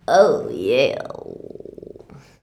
OOHYEAH.wav